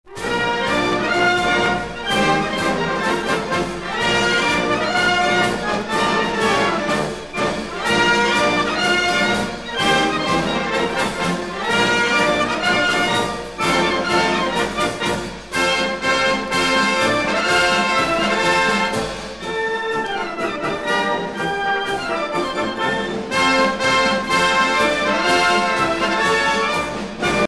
à marcher
militaire
Pièce musicale éditée